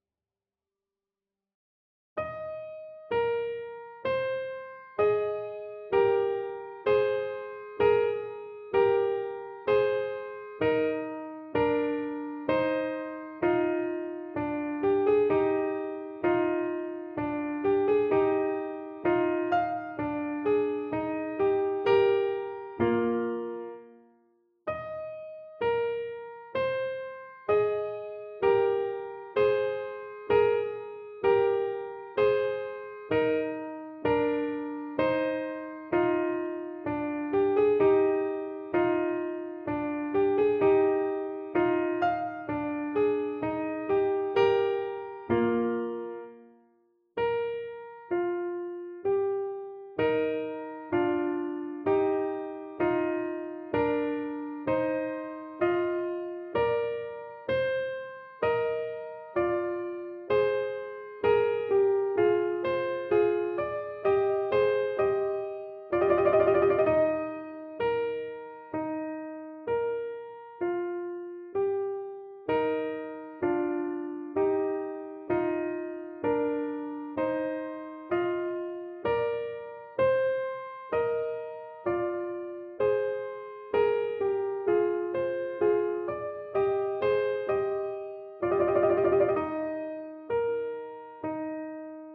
This sheet music is arranged for Violin and Viola.
» 442Hz